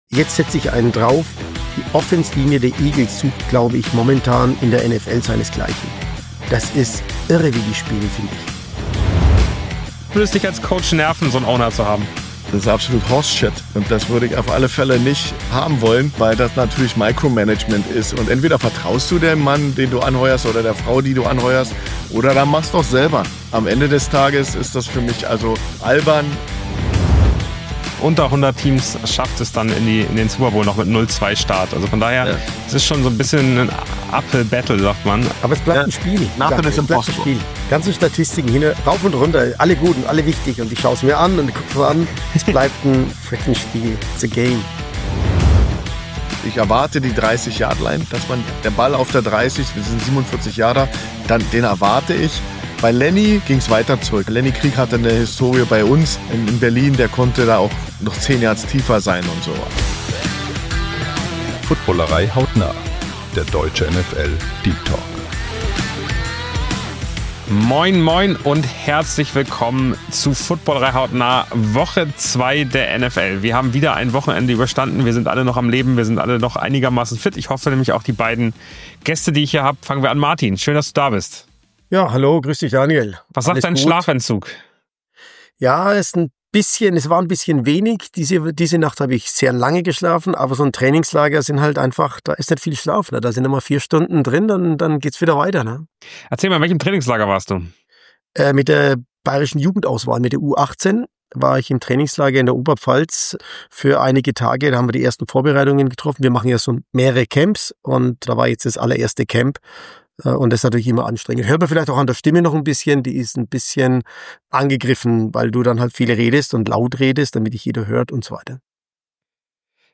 Der erste NFL-Deep-Talk - von Coaches für Fans.